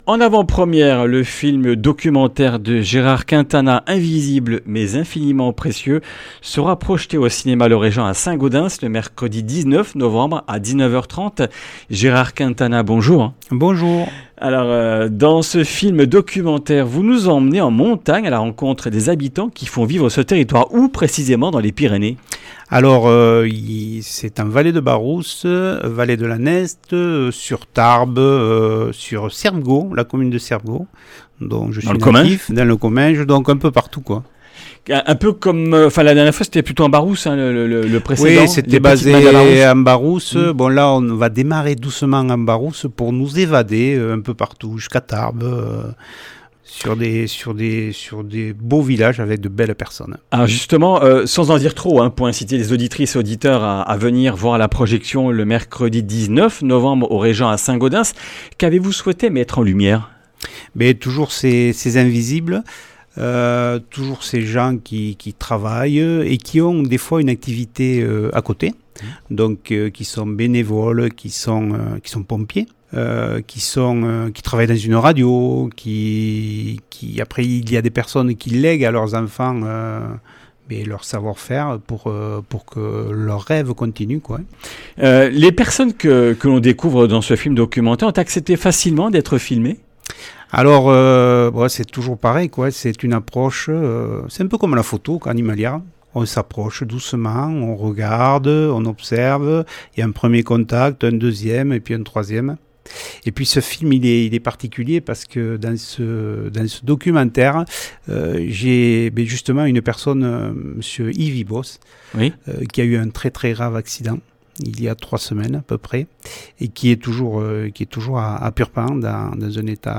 Comminges Interviews du 05 nov.